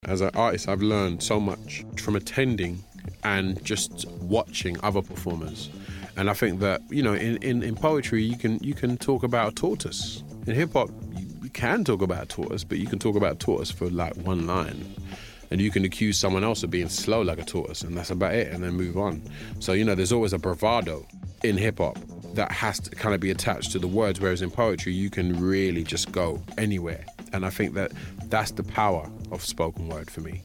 UK rapper Ty explains what he's learned from performing at spoken word night.
Teaser from BBC 1Xtra Stories: When Words Collide, which will be broadcast on Sunday 27th January.